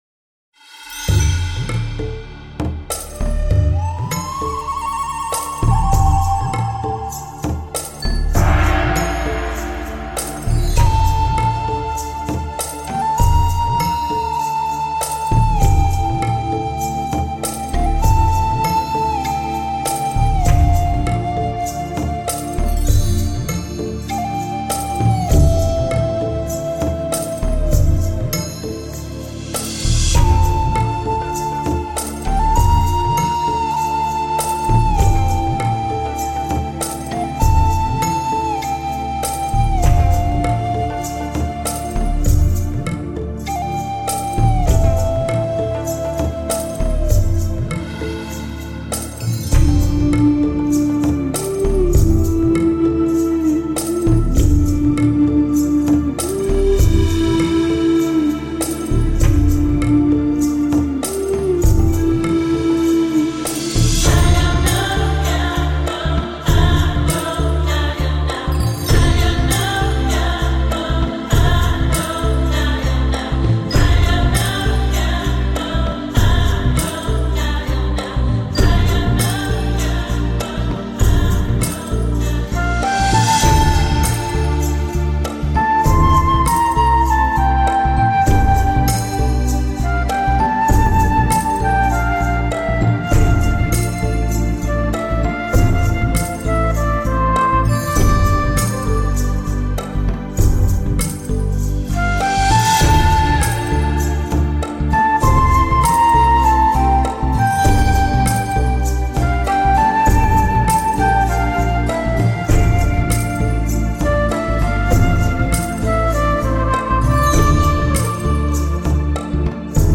新世纪
来自瑞士一尘不染的音符